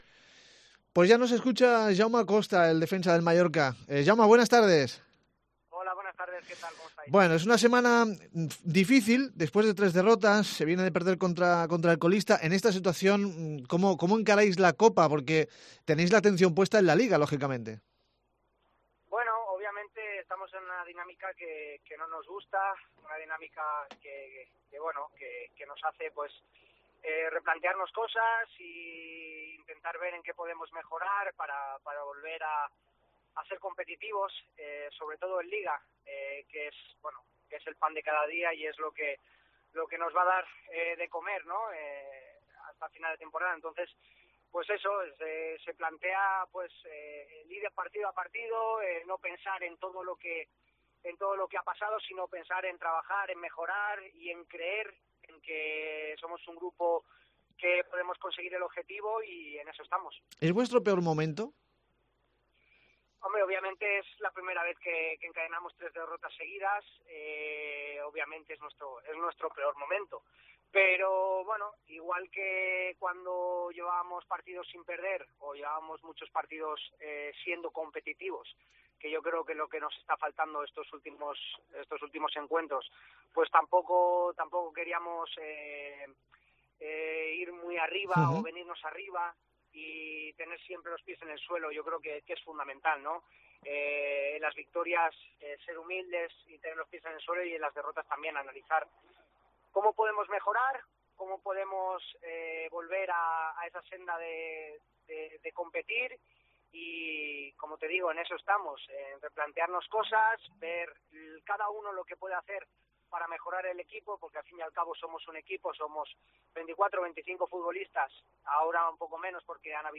El defensa del RCD Mallorca Jaume Costa atiende a Deportes Cope Baleares en el peor momento deportivo del equipo, como él mismo reconoce. Entiende las críticas pero cree que el equipo es un grupo comprometido y no duda de que van a contar con el apoyo de la afición. Analizamos la derrotas ante el Levante y el Barcelona, la Copa ante el Espanyol y más cosas.